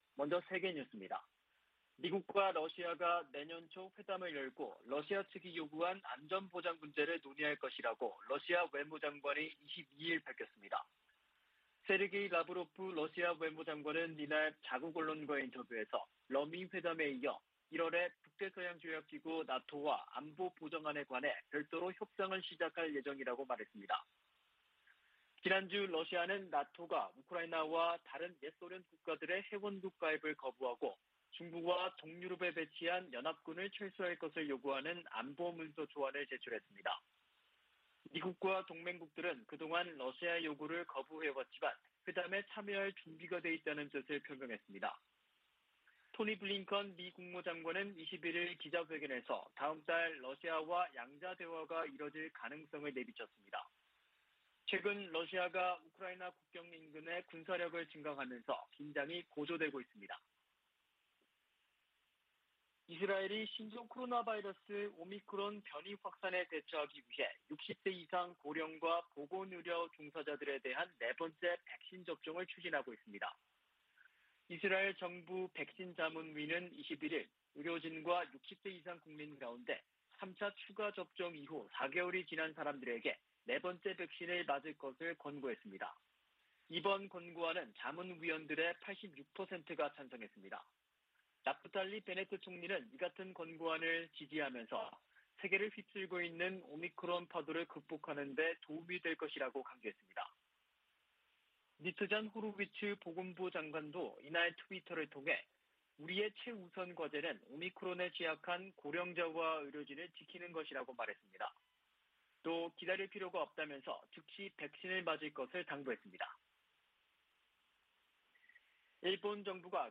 VOA 한국어 '출발 뉴스 쇼', 2021년 12월 23일 방송입니다. 조 바이든 미국 대통령은 2022년 회계연도에도 북한과 쿠바 등에 인도적 목적 이외 비무역 관련 지원을 하지 않을 것이라고 밝혔습니다. 토니 블링컨 미 국무장관은 인도태평양 지역에 정책과 자원을 집중하고 있다고 밝혔습니다. 미국과 한국은 한국의 증대된 역량과 자율성에 대한 열망을 감안해 동맹을 조정하고 있다고 미 의회조사국이 분석했습니다.